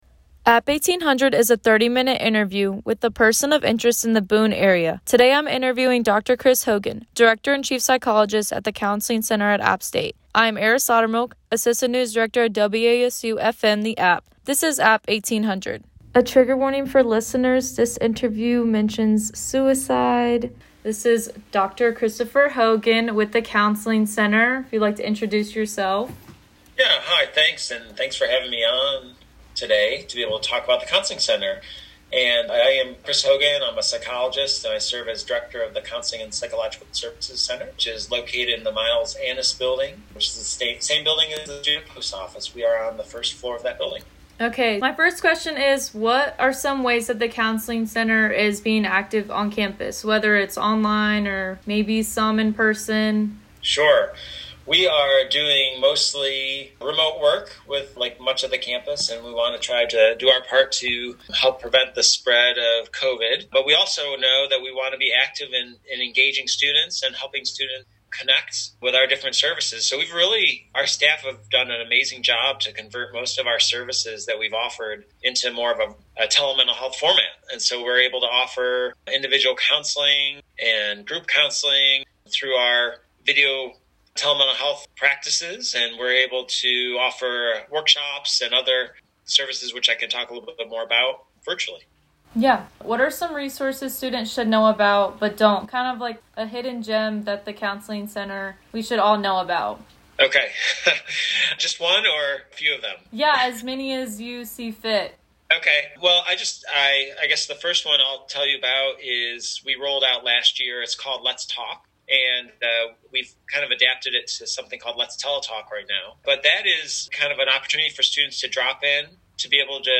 APP1800 is a 30-minute interview with a person involved in the Boone community.